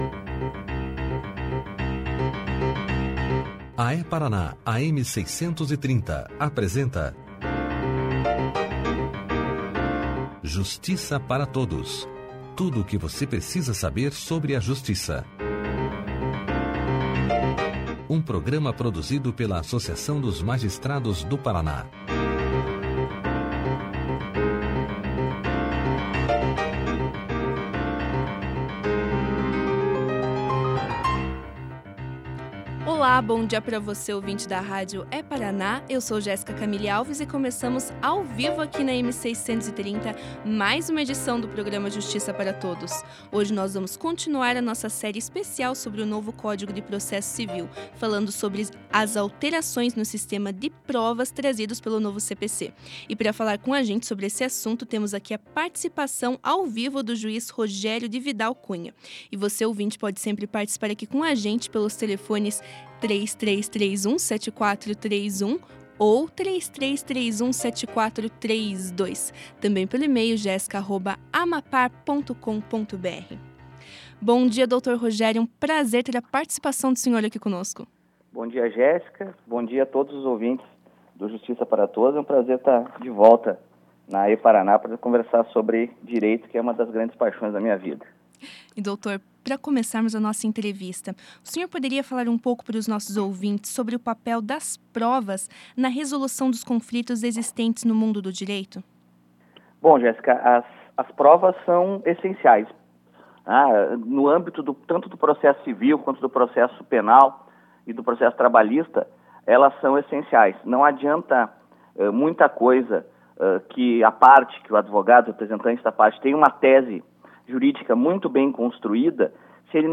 Clique aqui e ouça a entrevista do juiz Rogério de Vidal Cunha sobre as alterações no sistema de provas no novo CPC na íntegra.